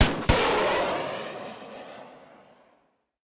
javelin_far.ogg